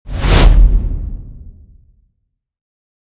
Download Whoosh sound effect for free.
Whoosh